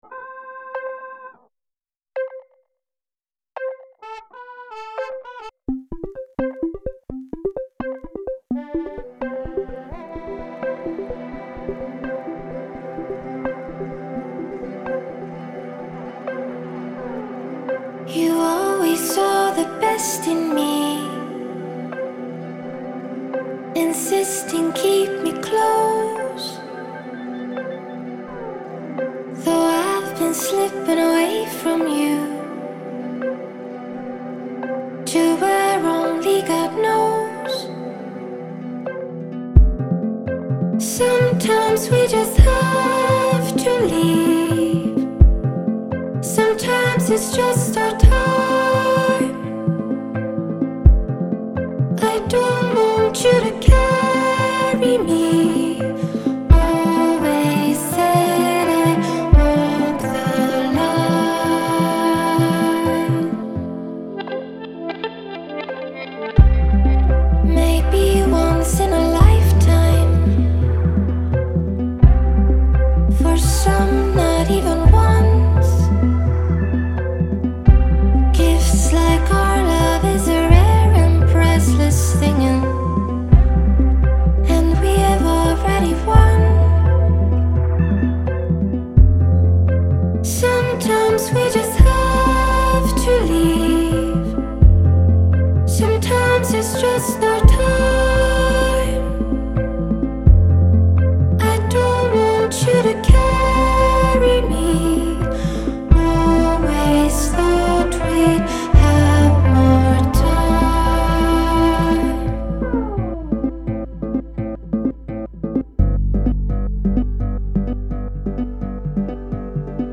Over a peaceful, arpeggiated bed of boops and bips